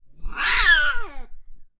Angry Cat Meow